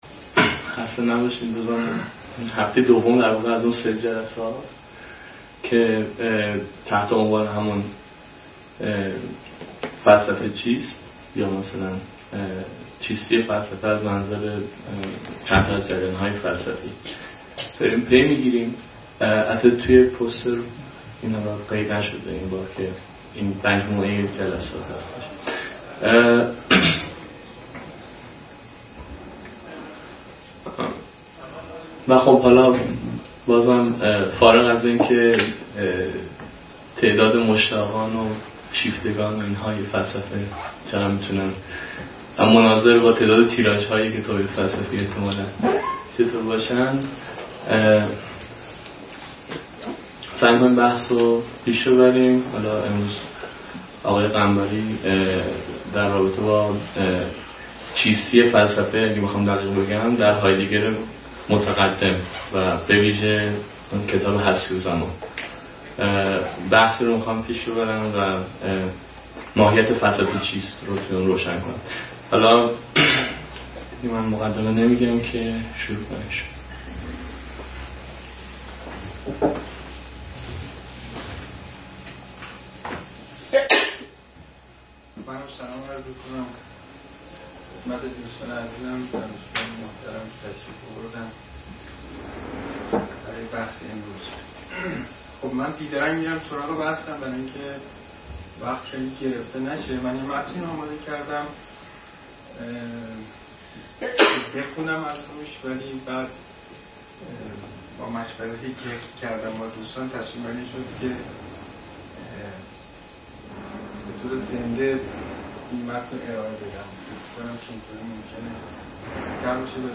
سخنرانی
در سلسله جلسات «فلسفه چیست؟» است که به همت انجمن علمی دانشگاه شهید بهشتی در ۴ اسفندماه سال ۱۳۹۳ در این دانشگاه برگزار شد.